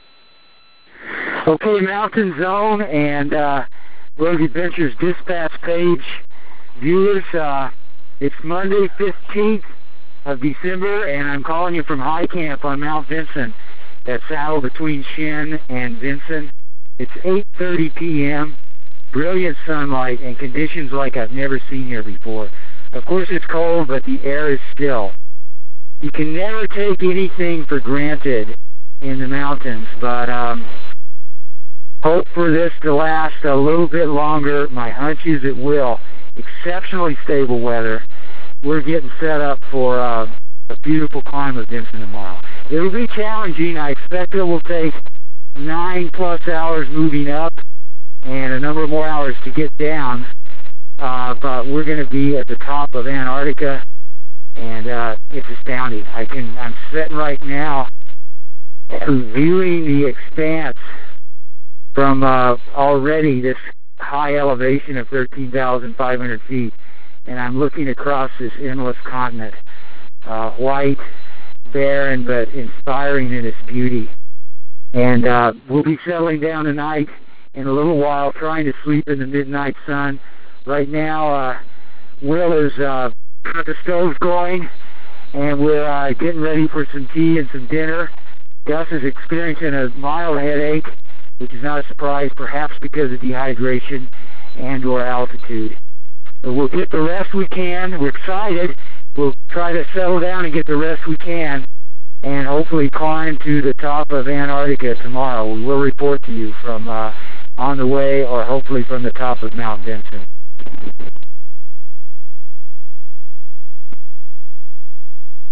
Berg Adventures International: 2003 Mt. Vinson Expedition and Cybercast.